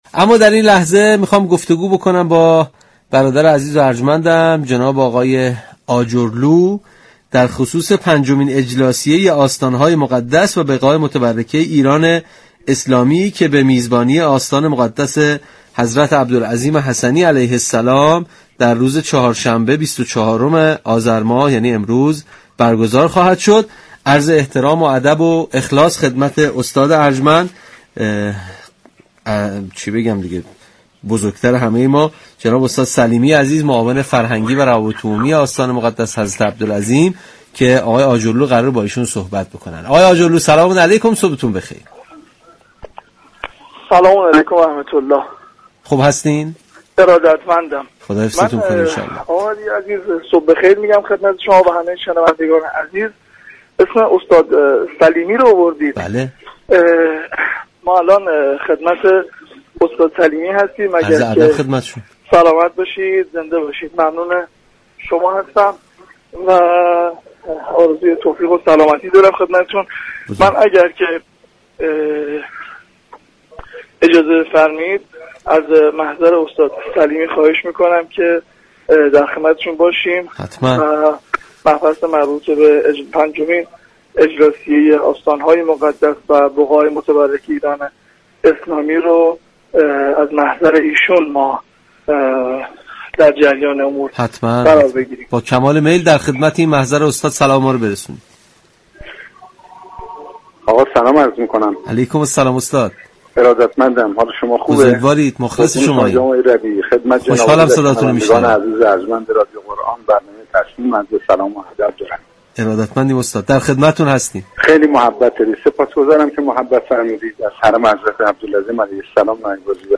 در گفتگو با برنامه تسنیم رادیو قرآن به تشریح برنامه‌های پنجمین اجلاسیه آستان‌های مقدس و بقاع متبركه ایران اسلامی پرداخت.